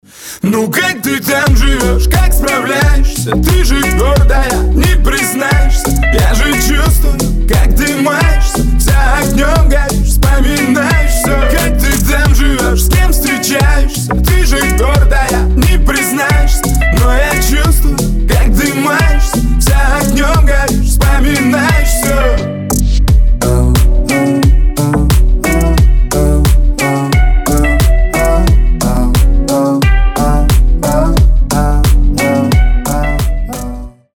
Медленные , Ритмичные